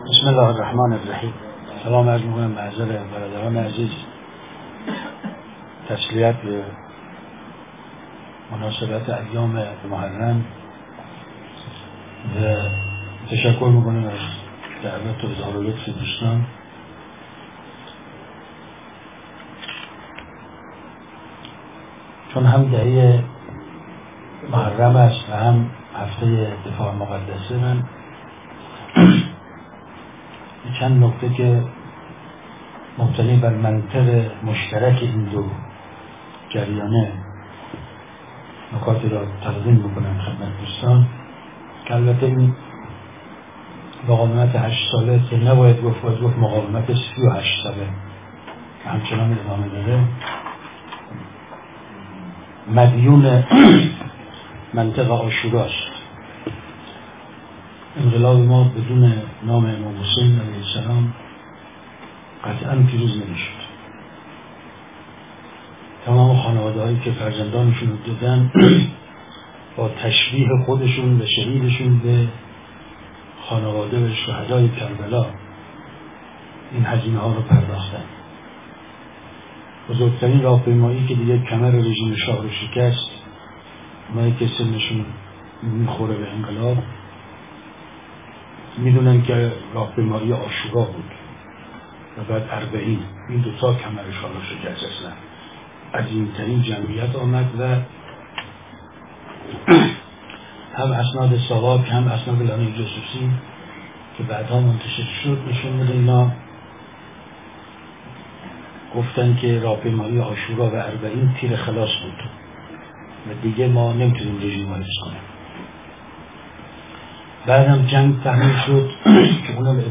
یادداشت شفاهی/ حسن رحیم‌پور ازغدی